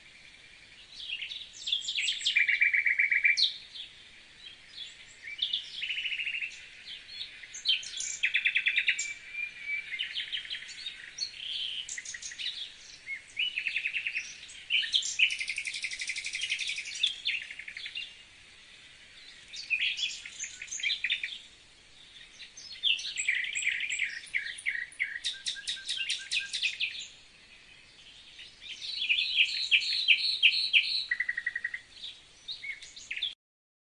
婉转动听的夜莺鸣叫声